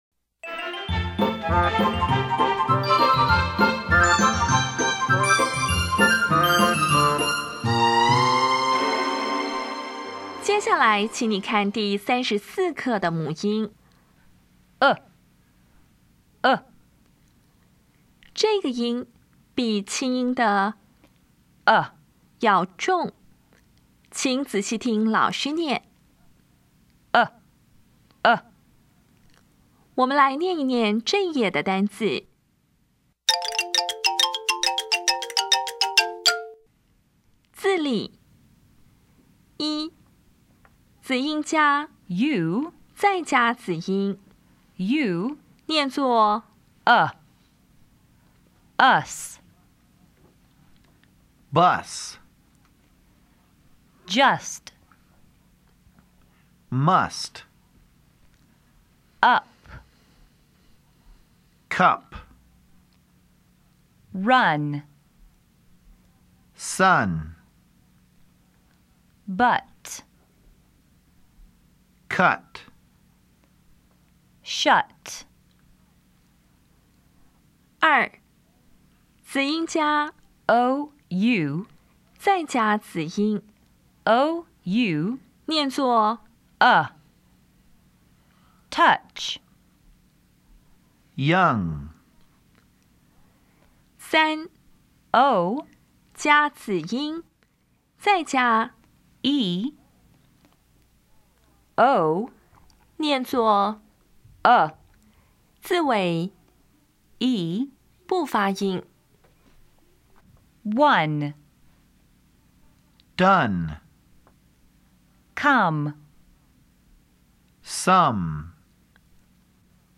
音标讲解第三十四课
比较[æ][ʌ]
比较[ʌ] 与 [ɑ]